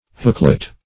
hooklet - definition of hooklet - synonyms, pronunciation, spelling from Free Dictionary Search Result for " hooklet" : The Collaborative International Dictionary of English v.0.48: Hooklet \Hook"let\, n. A little hook.